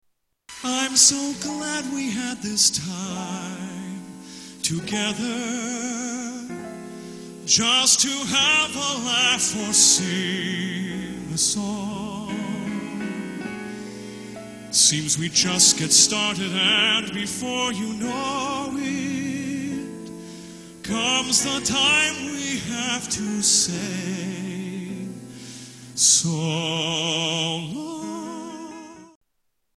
TV Theme Songs